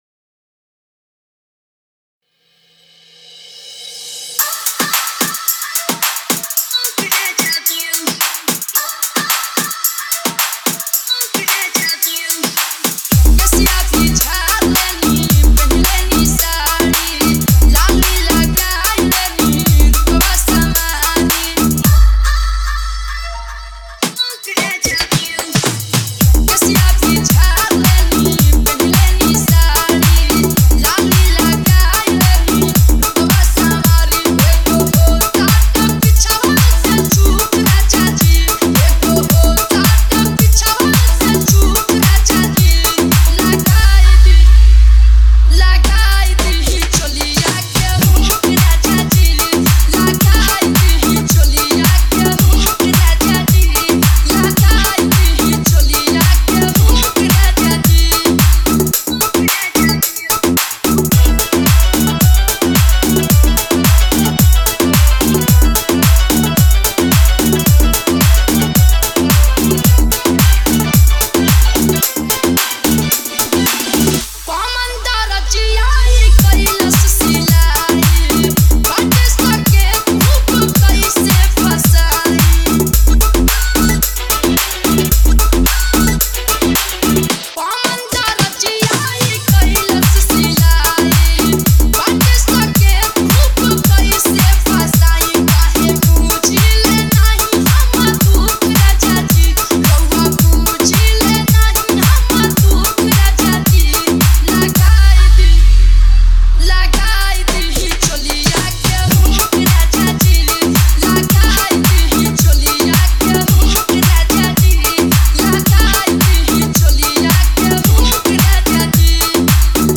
Category : Bhojpuri Remix Song